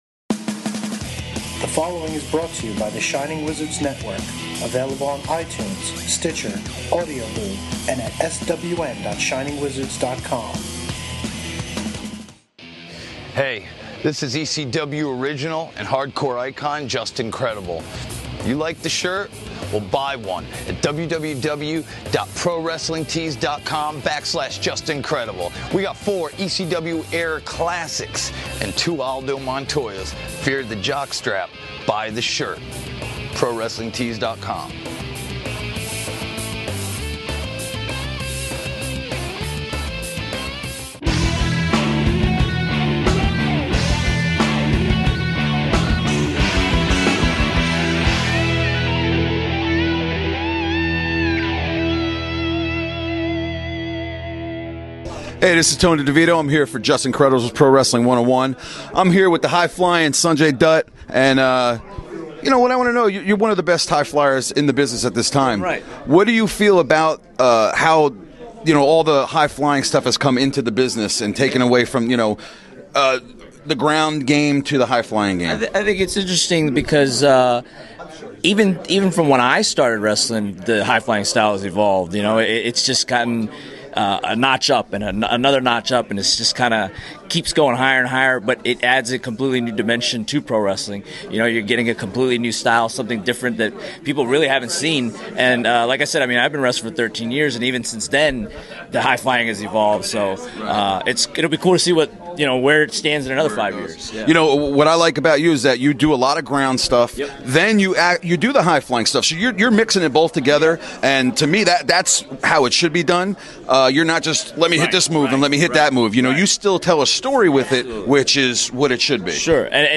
Pro Wrestling 101 special guest interviewer